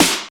46.10 SNR.wav